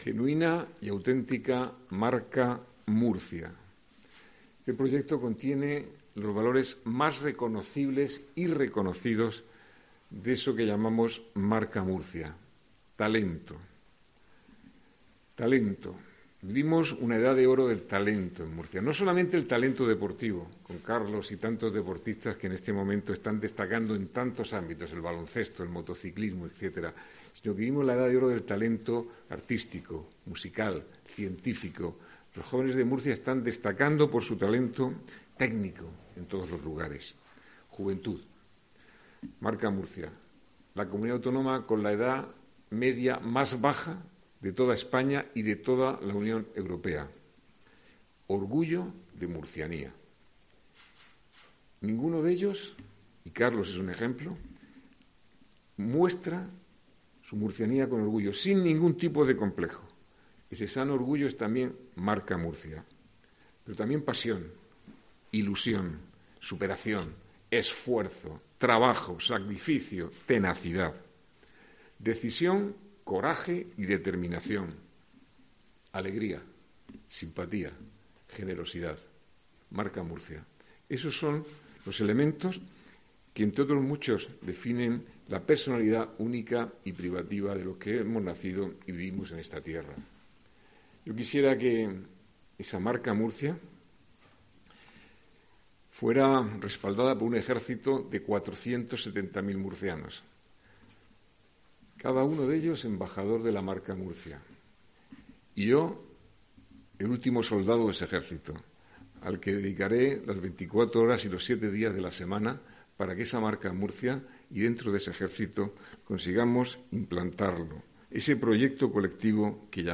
Así lo ha anunciado esta mañana el alcalde Ballesta durante la presentación de la Fase I del proyecto estratégico deportivo, en una rueda de prensa en la que también han estado presentes la vicealcaldesa y concejal de Fomento y Patrimonio, Rebeca Pérez; el concejal de Deportes, Miguel Ángel Noguera, y la presidenta de la Junta Municipal de El Palmar, Verónica Sánchez.